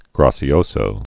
(gräsē-ōsō)